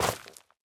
Minecraft Version Minecraft Version latest Latest Release | Latest Snapshot latest / assets / minecraft / sounds / block / netherwart / step1.ogg Compare With Compare With Latest Release | Latest Snapshot
step1.ogg